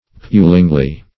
pulingly - definition of pulingly - synonyms, pronunciation, spelling from Free Dictionary Search Result for " pulingly" : The Collaborative International Dictionary of English v.0.48: Pulingly \Pul"ing*ly\, adv.
pulingly.mp3